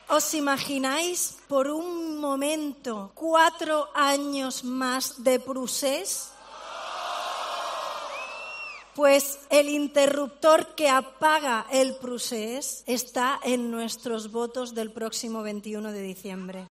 El acto central de Ciudadanos se desarrolló en la Fira de Barcelona, donde la formación naranja consiguió reunir a unas 4.000 persomas en un mitin multitudinario y que incluyó actuaciones y chirigotas.
Arrimadas, en un discurso tranquilo y sosegado, apeló al “hambre de cambio” de los catalanes para “dejar atrás el ‘procés’”.